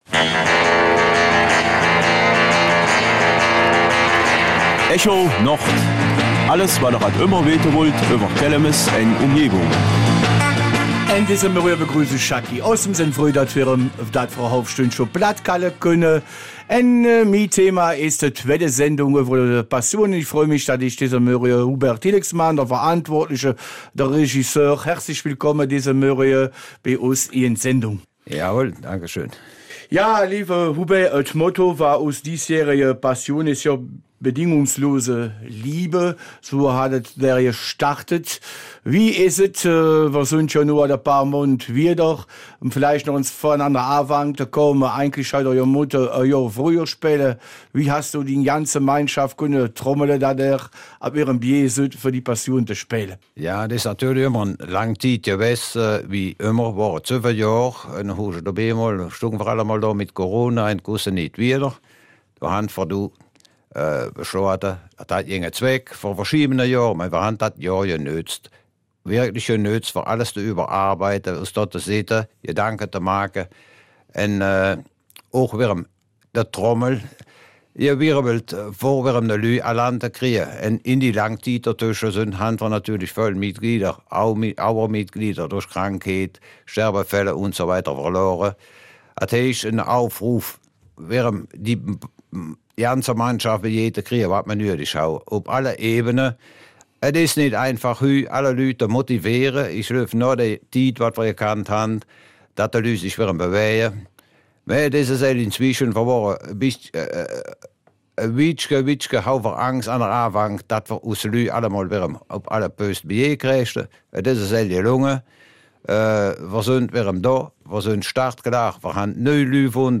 Kelmiser Mundart: Passionsspiele in Kelmis